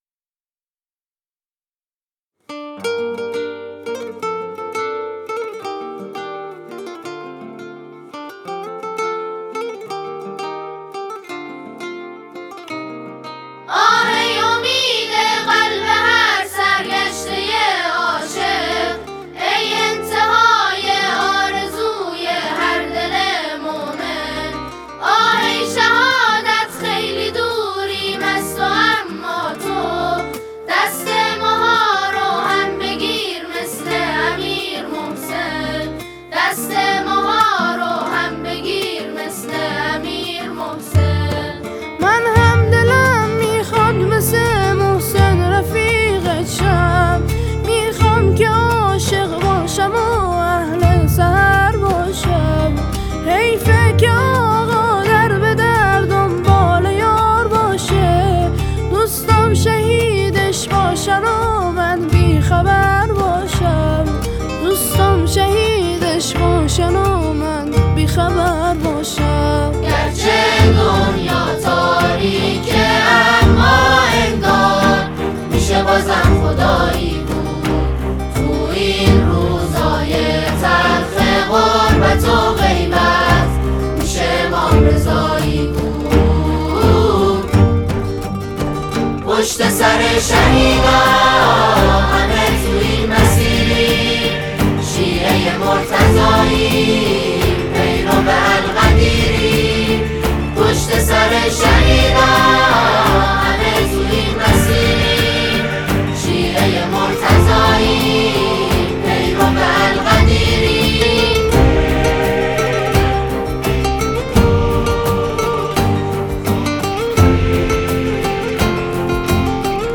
گروه سرود یزد